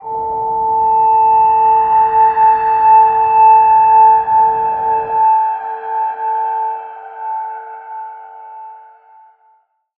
G_Crystal-A5-f.wav